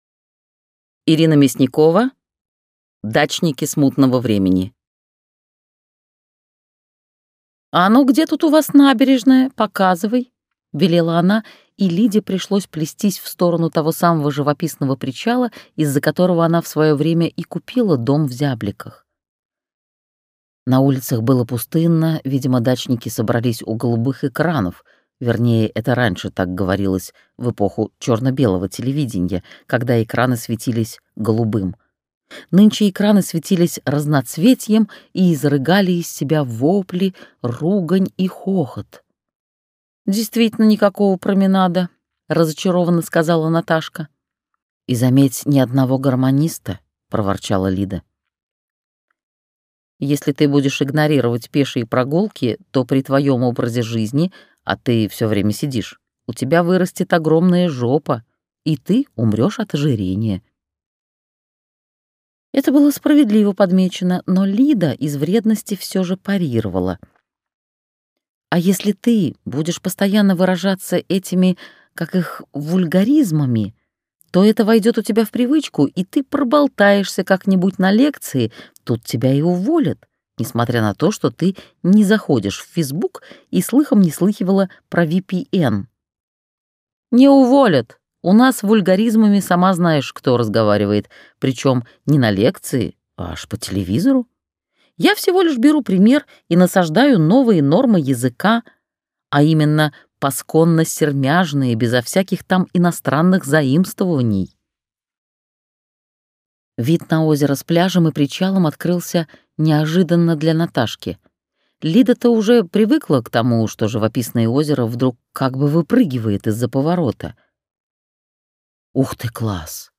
Аудиокнига Дачники смутного времени | Библиотека аудиокниг